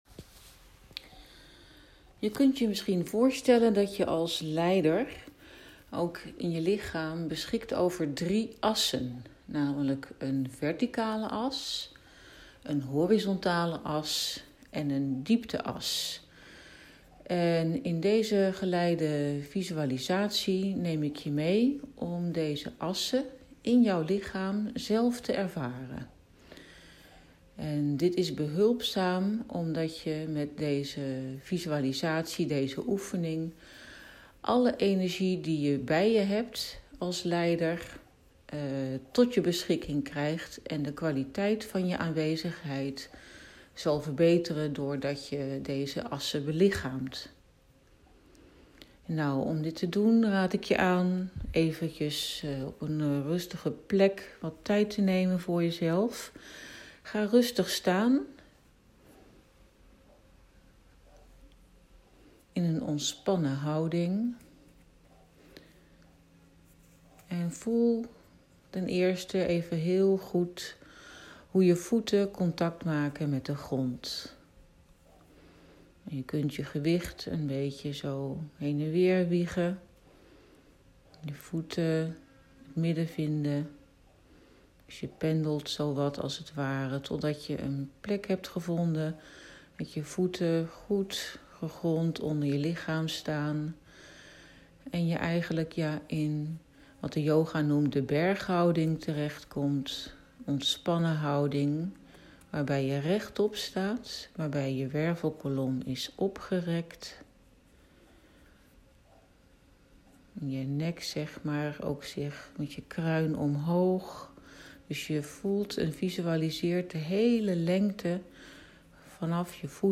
Drie leiderschapsassen Wij onderscheiden drie leiderschapsassen die je als leidinggevende belichaamt. In deze geleide meditatie nodigen we je uit om stil te staan bij deze drie assen en ze te ervaren.
Geleide-visualisatie-leiderschapsassen.mp3